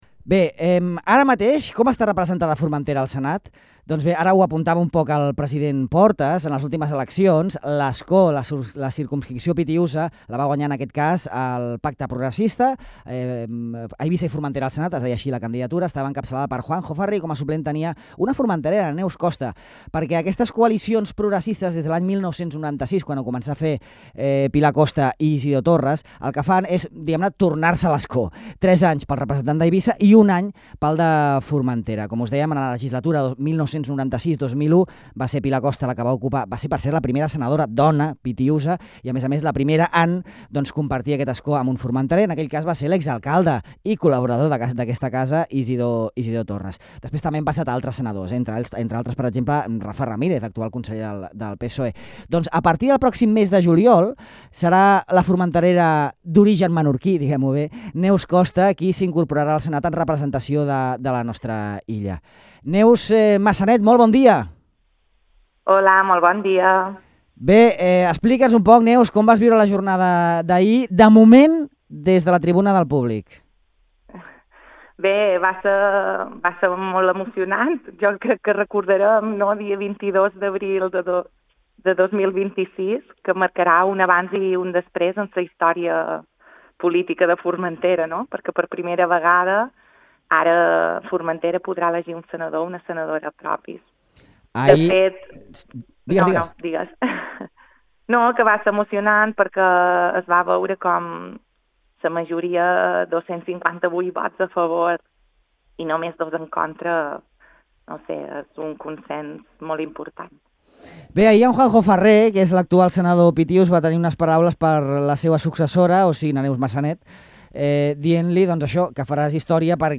Des de la tribuna del Senat també va viure la votació la futura senadora Neus Massanet, que assumirà l’escó de la circumscripció pitiüsa el pròxim juliol. En el següent reproductor podeu escoltar la seva intervenció a Ràdio Illa.